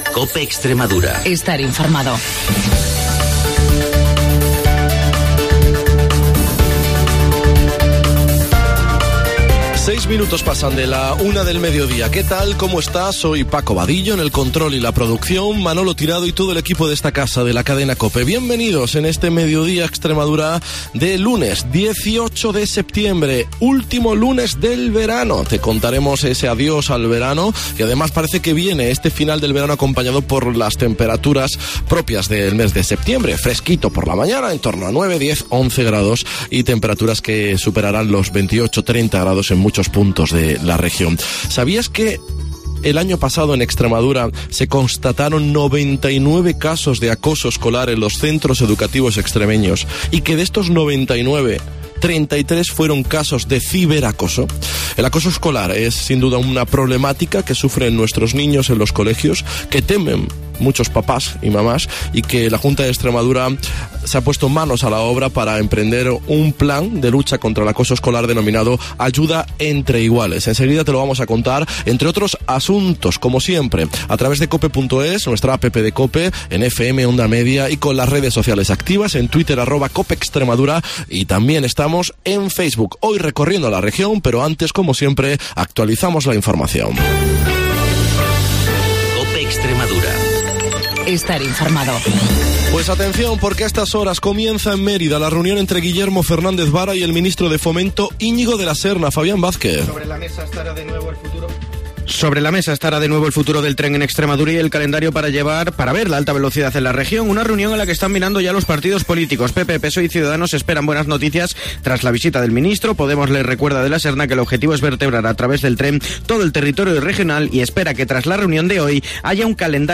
El programa líder de la radio extremeña